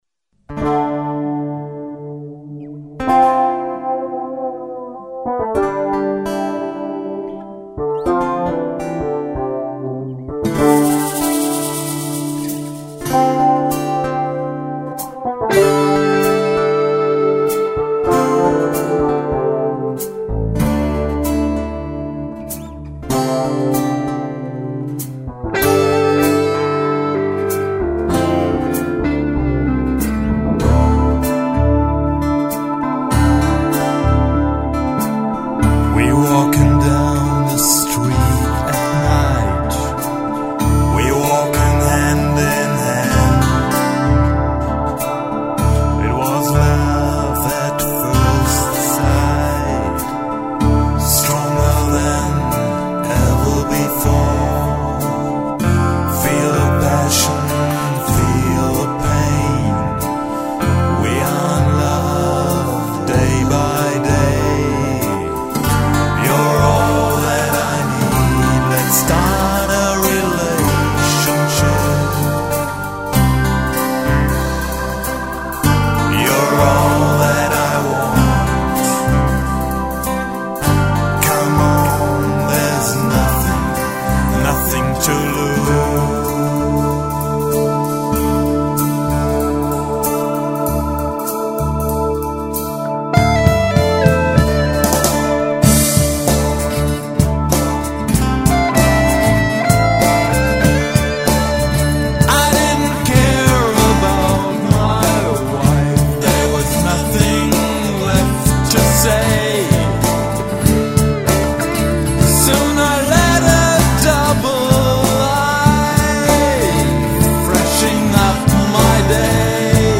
aufgenommen und abgemischt im eigenem
Eine melodiöse Pop-Rock-Produktion.
rockigen und folkigen Gitarrenelementen.